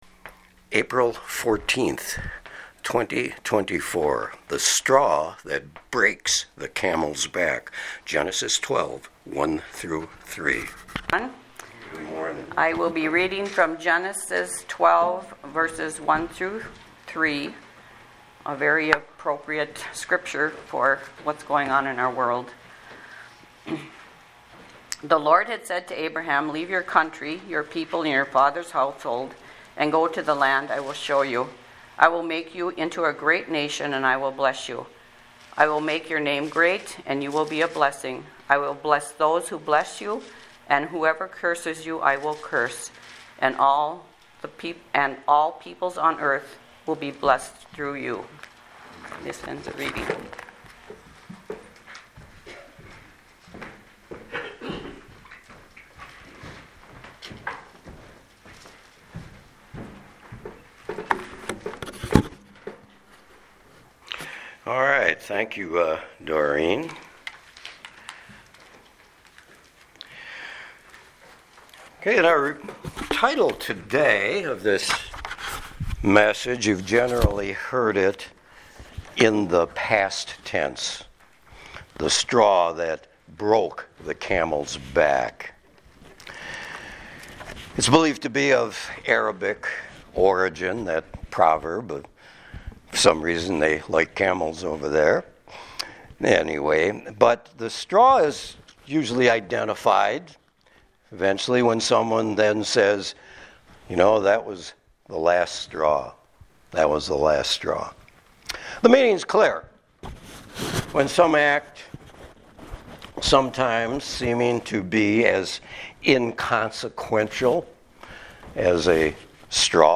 Sermons | Sand Lake Chapel